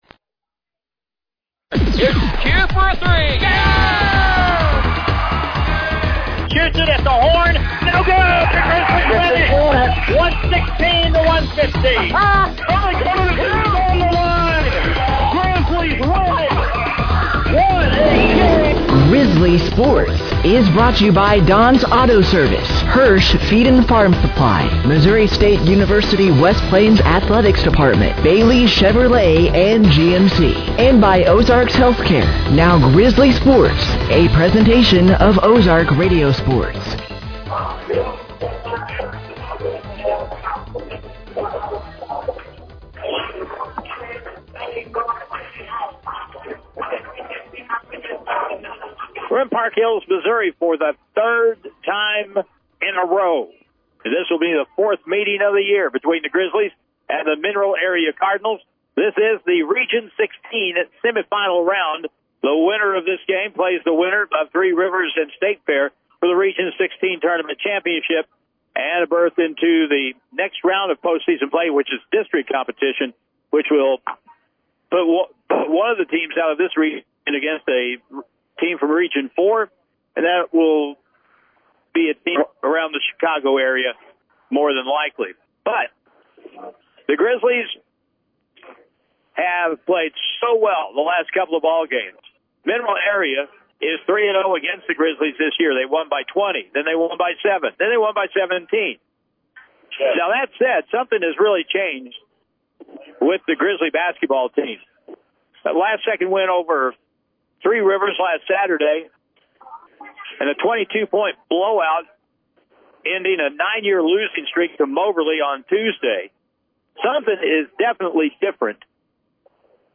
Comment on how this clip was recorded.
The Missouri State West Plains Grizzly Basketball Team traveled back to The Bob Seacrest Field House on The campus of Mineral Area College for Region-Semi final play with The Mineral Area Cardinals on Thursday, March 5th, 2026.